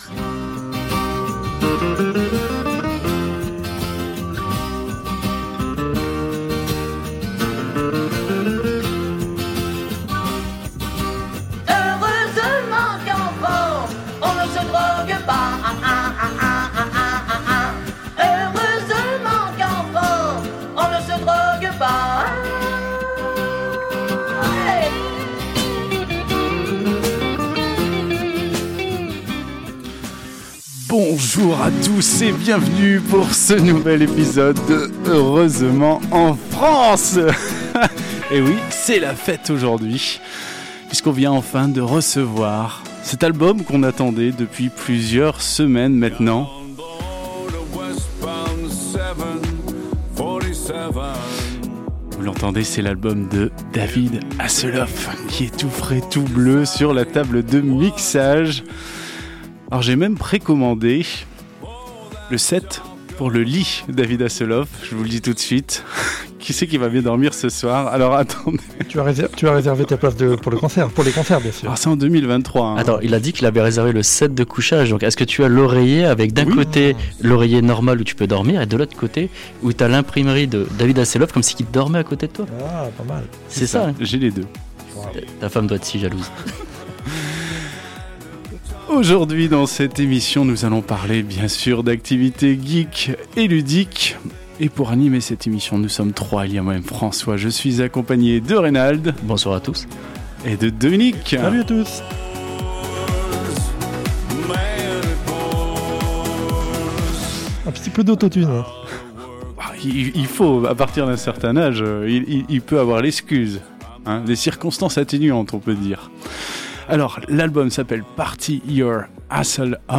Au sommaire de cet épisode diffusé le 3 octobre 2021 sur Radio Campus 106.6 :